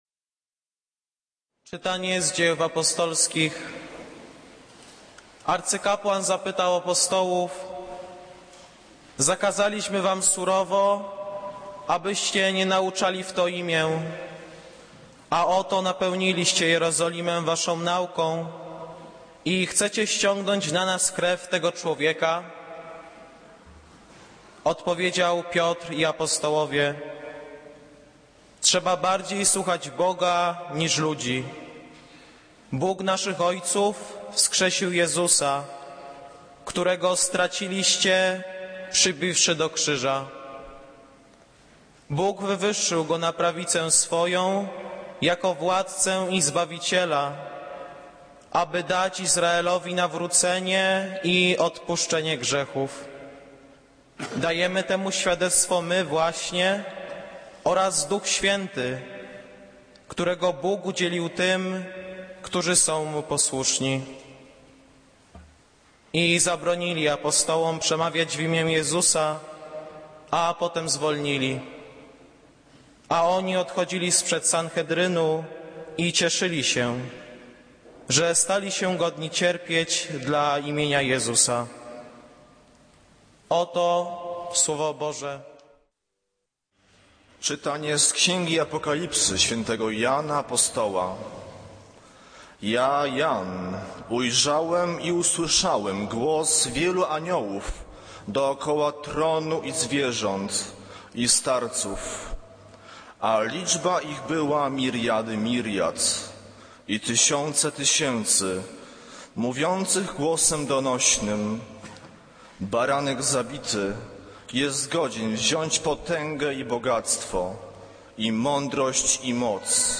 Kazanie z 14 kwietnia 2013r.